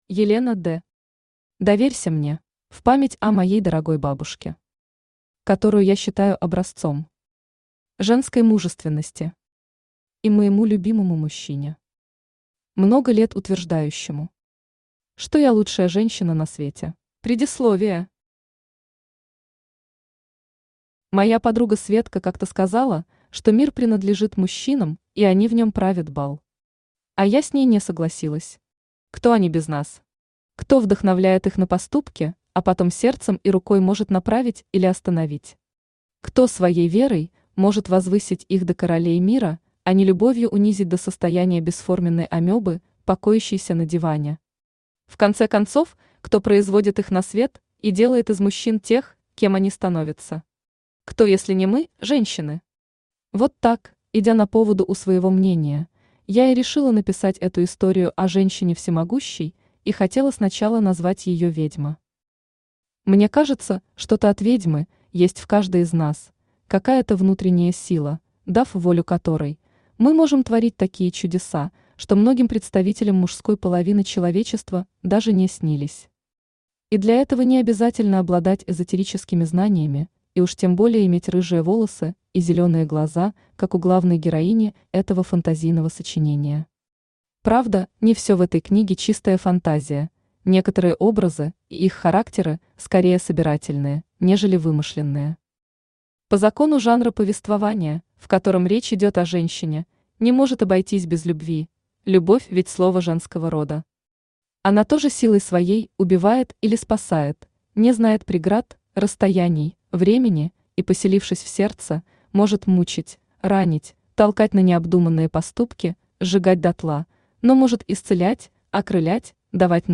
Aудиокнига Доверься мне Автор Елена Д. Читает аудиокнигу Авточтец ЛитРес.